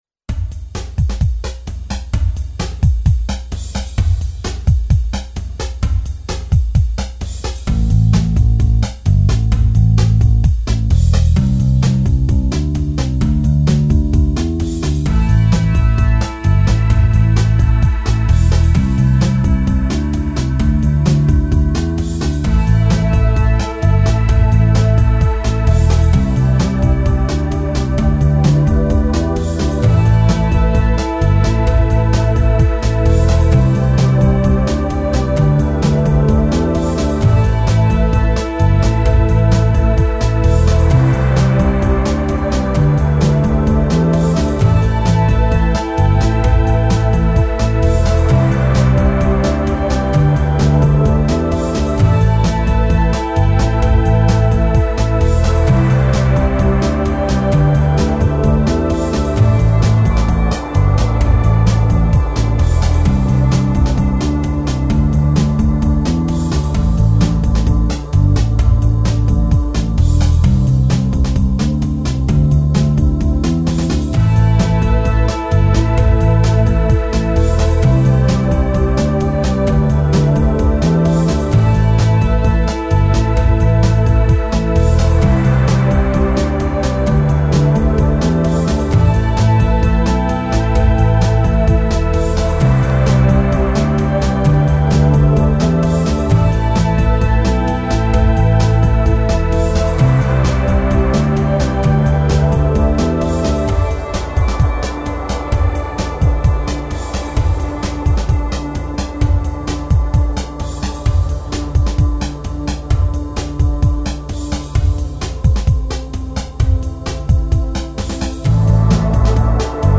Tech Groove